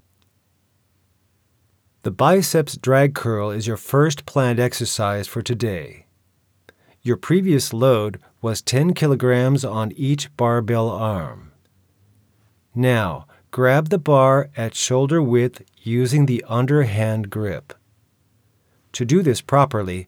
Streamline Audacity Recording & Editing for Voice Overs
I ran it through straight Audiobook Mastering and this came out. No noise reduction.
Being an instructor doesn’t mean you have to go slow.